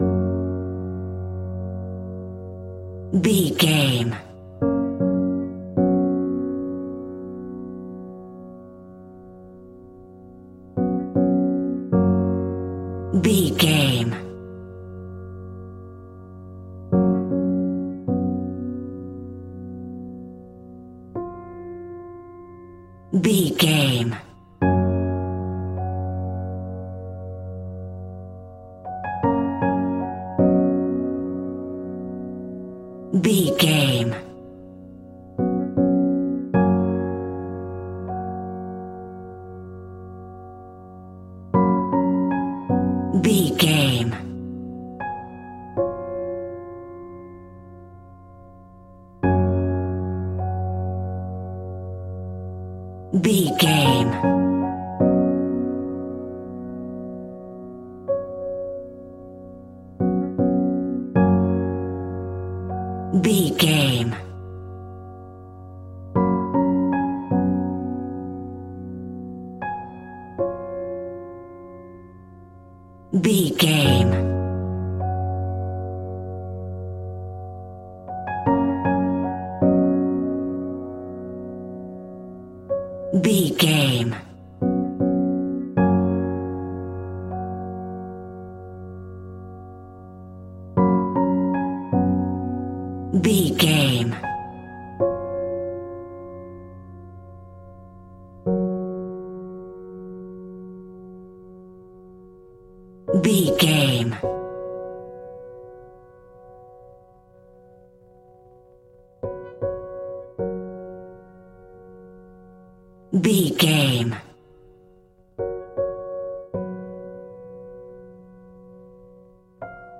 Soft warm and touching piano melodies in a major key.
Regal and romantic, a classy piece of classical music.
Ionian/Major
G♭
regal
romantic